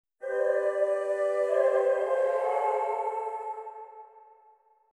各エフェクトの Mix と、Wet音だけを抽出したものです。
Sonitus Wet
ボーカル・ホール系のプリセットを、ほぼそのまま AUX に置き、リターン量が同じになるように設定。
Sonitus_wet.mp3